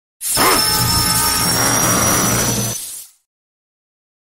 Tiếng Xịt khói, gầm gừ, hét giận dữ…
Thể loại: Hiệu ứng âm thanh
Description: Tiếng xịt khói phì phò, rít gào giận dữ vang lên như hơi nước bùng nổ, hòa cùng tiếng gầm gừ, hét thét, gào rú đầy phẫn nộ, tiếng rống lên dữ dội, tạo cảm giác căng thẳng nghẹt thở. Hiệu ứng này như tiếng nổ phun, khạc khói, phì phì, gầm rú, rít chói tai, cực kỳ phù hợp làm sound effect trong video hành động, chiến đấu hay cao trào kịch tính, nhấn mạnh cơn giận bùng nổ mãnh liệt.
tieng-xit-khoi-gam-gu-het-gian-du-www_tiengdong_com.mp3